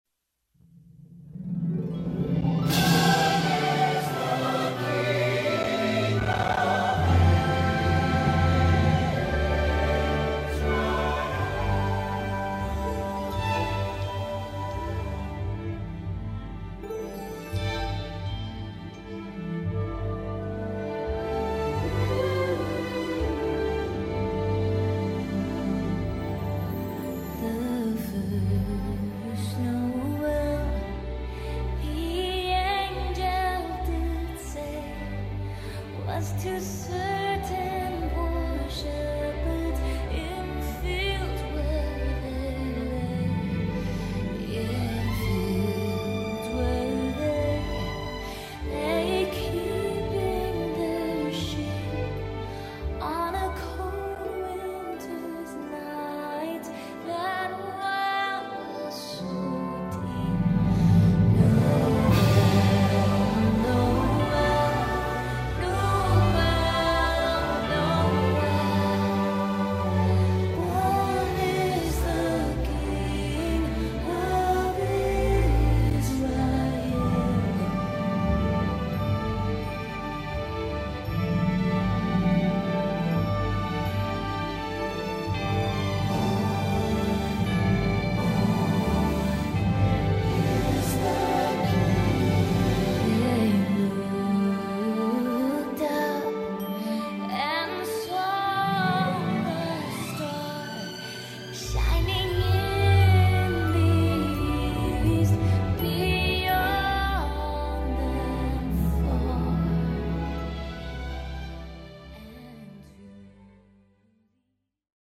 Demo Vocal